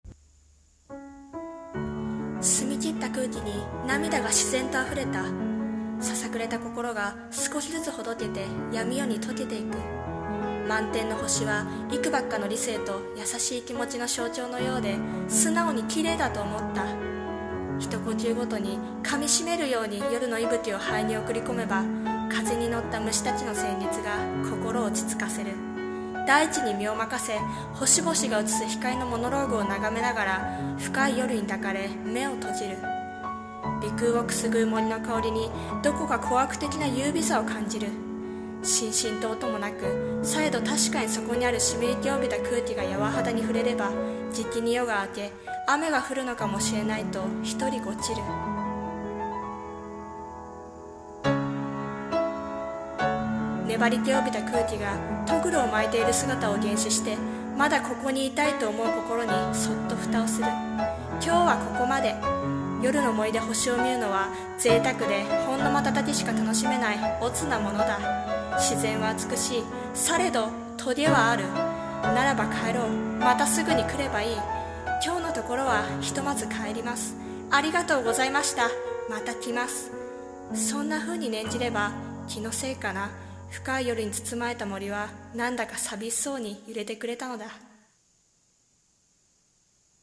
夜の森に星 声劇